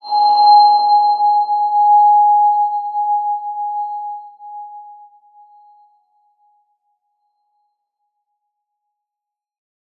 X_BasicBells-G#3-pp.wav